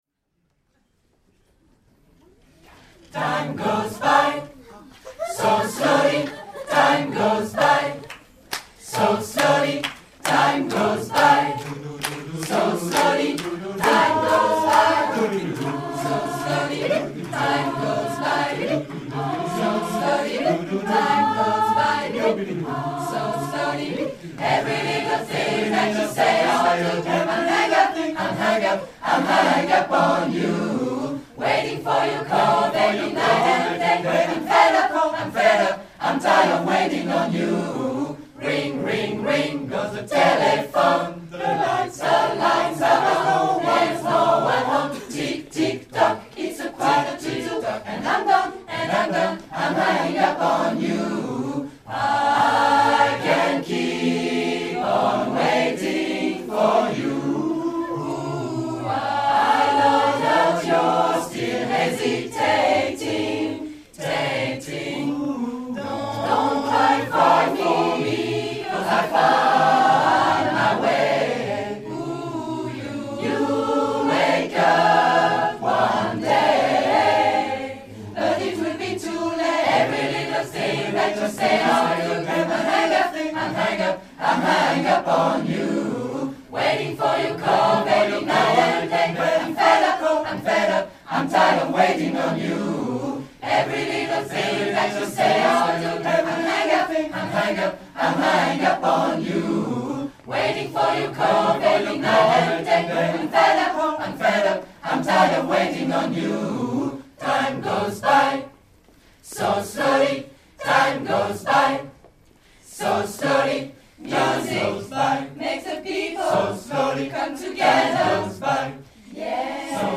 HISTORIQUE DES CHANTS HARMONISES & INTERPRETES DEPUIS 2005